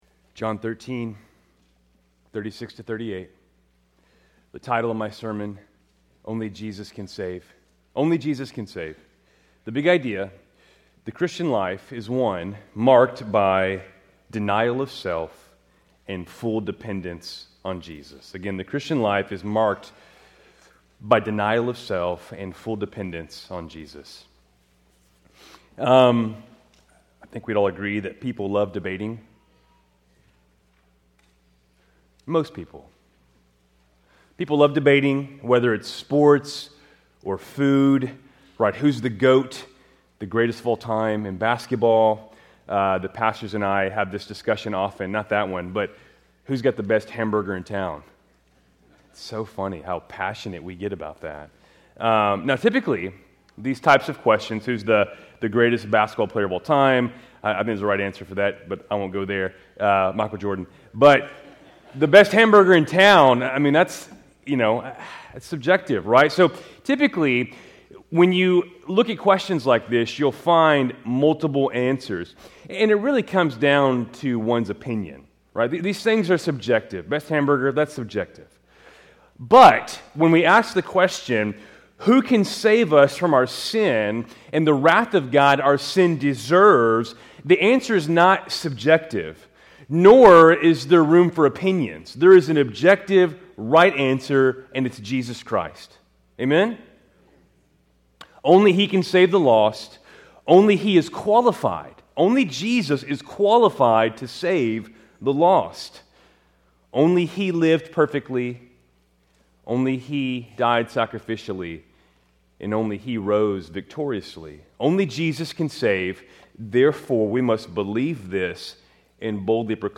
Keltys Worship Service, July 6, 2025